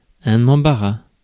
Ääntäminen
France: IPA: [ɑ̃.ba.ʁa]